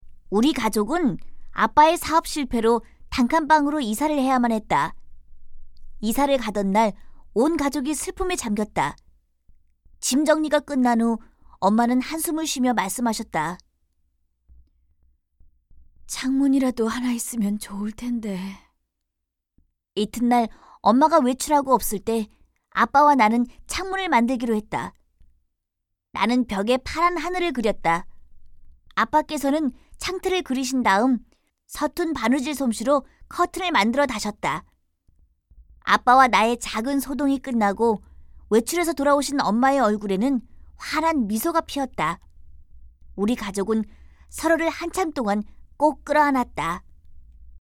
091쪽-내레이션.mp3